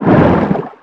Sfx_creature_hiddencroc_swim_fast_01.ogg